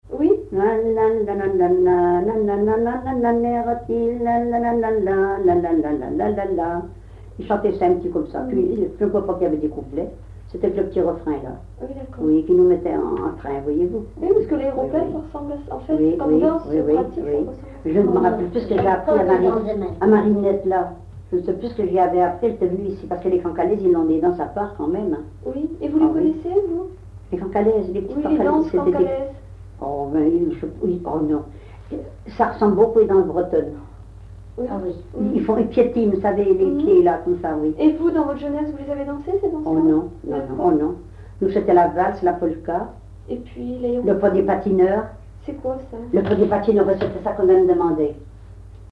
Localisation Cancale (Plus d'informations sur Wikipedia)
Usage d'après l'analyste gestuel : danse ;
Genre brève
Catégorie Pièce musicale inédite